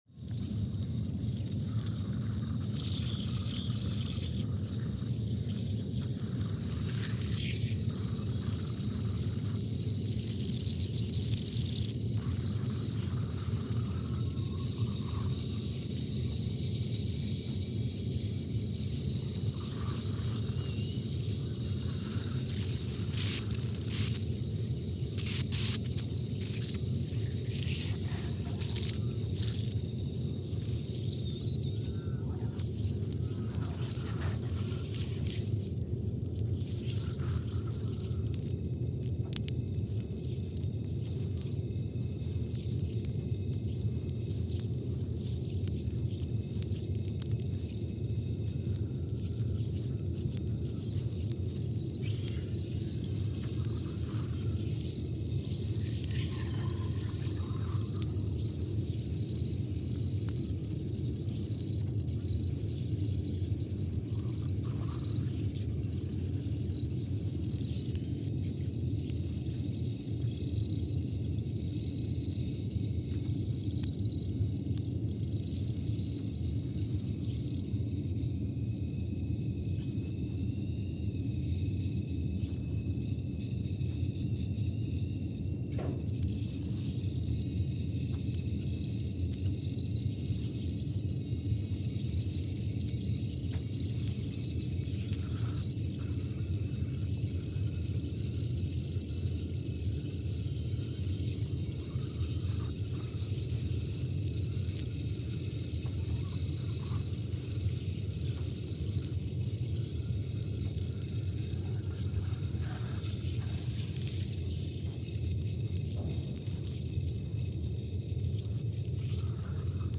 Scott Base, Antarctica (seismic) archived on November 26, 2019
Sensor : CMG3-T
Speedup : ×500 (transposed up about 9 octaves)
Loop duration (audio) : 05:45 (stereo)
SoX post-processing : highpass -2 90 highpass -2 90